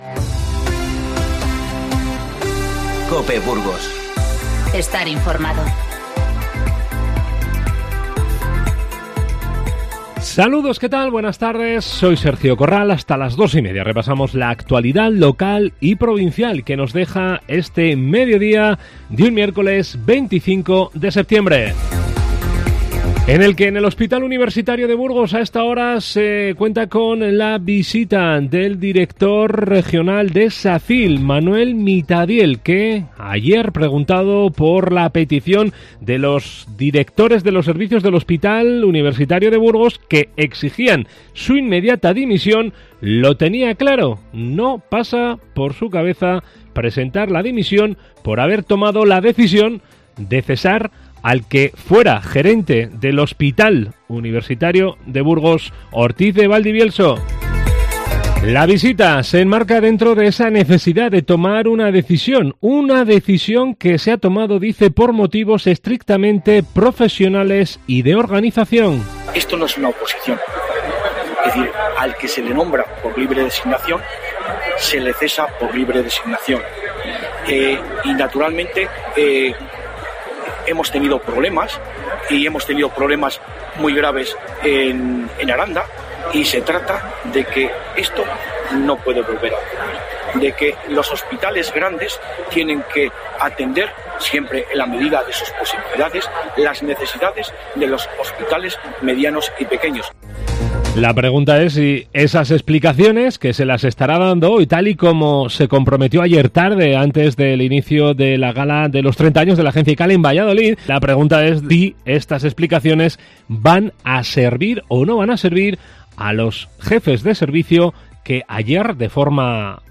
INFORMATIVO Mediodía 25-9-19